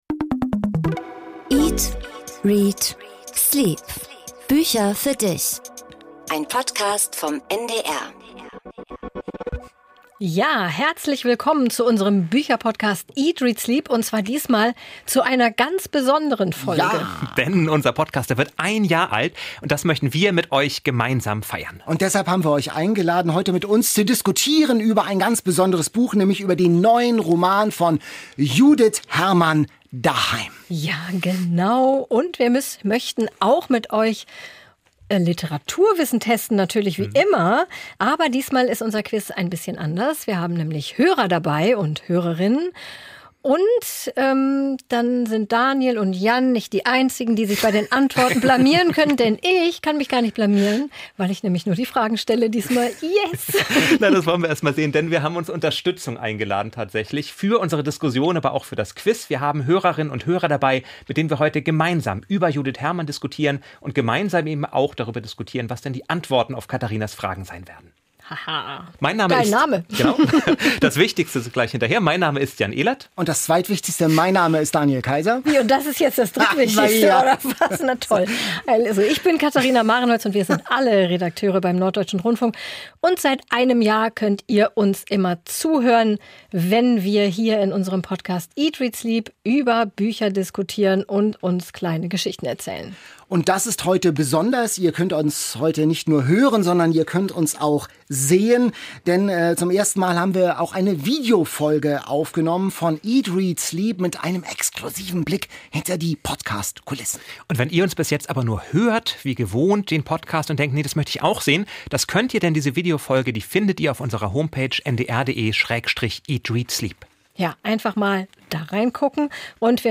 Anlässlich des einjährigen Jubiläums gibt es nämlich eine Videofolge von eat.READ.sleep. Die zweite Premiere
Und: Zum ersten Mal sind auch Hörer und Hörerinnen eingeladen und per Video zugeschaltet. Diese diskutieren heftig mit den drei Gastgebern.